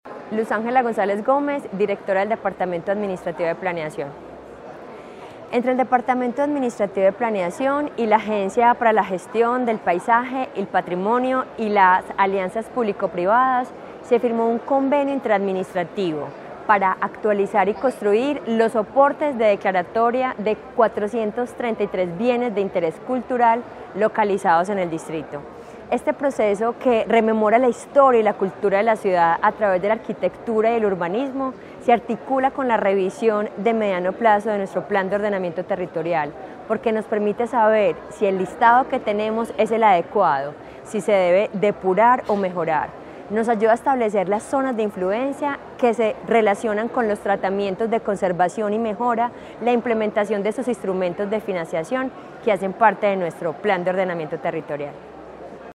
Declaraciones de la directora del Departamento Administrativo de Planeación, Luz Ángela González En 2006, fue la última vez que Medellín renovó el listado de los bienes inmuebles de los grupos arquitectónico y urbano declarados como Bienes de Interés Cultural.
Declaraciones-de-la-directora-del-Departamento-Administrativo-de-Planeacion-Luz-Angela-Gonzalez.mp3